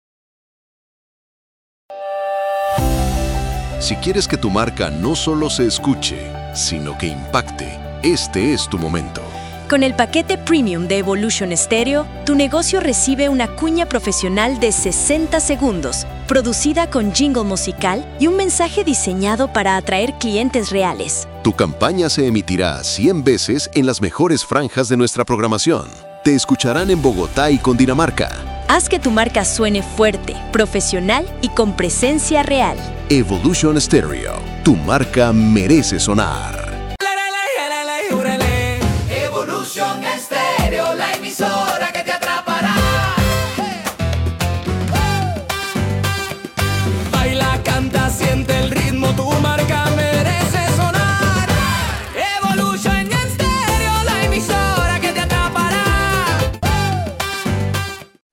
Audio institucional de Evolution Stereo   Presentación de la emisora y respaldo del servicio.
CUNA-HAZ-QUE-TU-MARCA-SUENE-PREMIUM.mp3